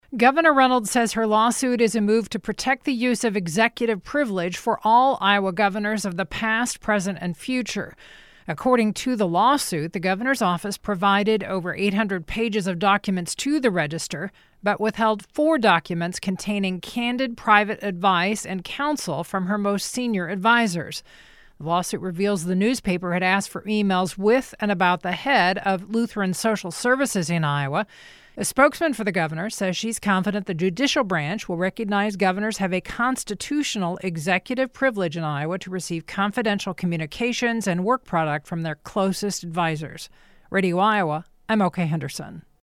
Radio Iowa Reporter